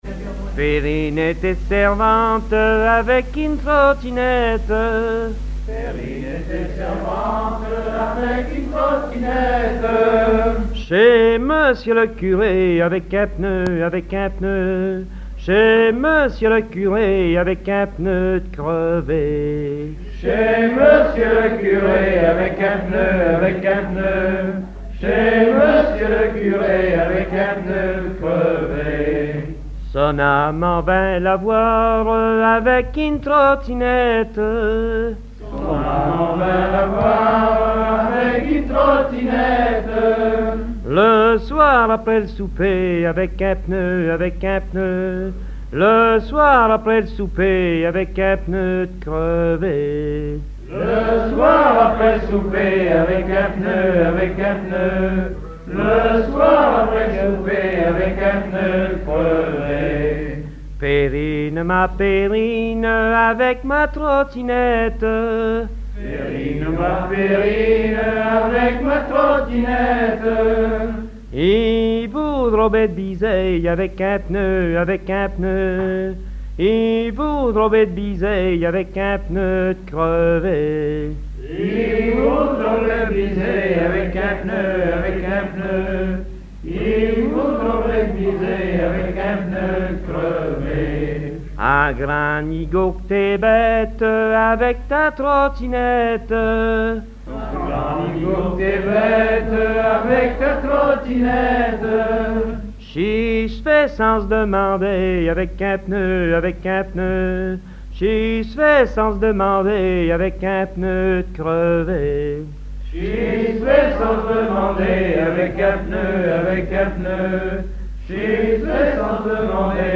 Genre laisse
collectif à Orouet
Pièce musicale inédite